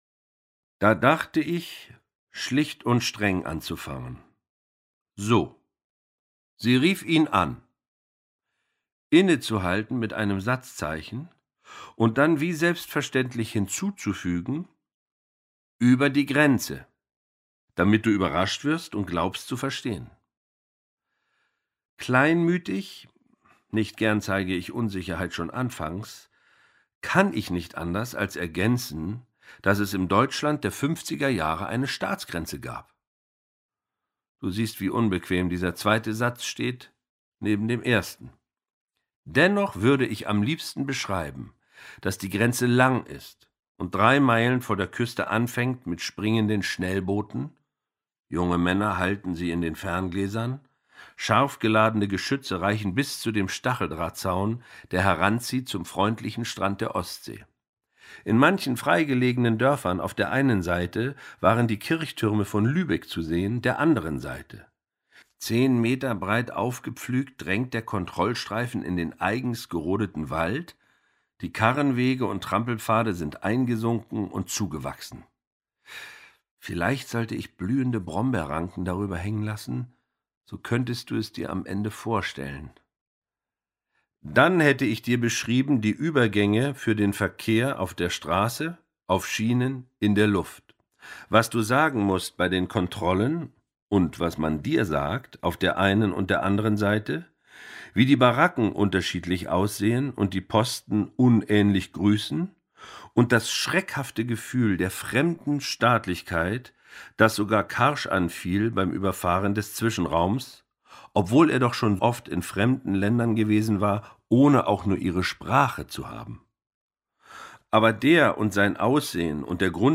Charly Hübner (Sprecher)
2021 | Ungekürzte Lesung
Durch Charly Hübners authentische Lesung wird Johnsons konzentrierte und eigenwillige Sprache erlebbar.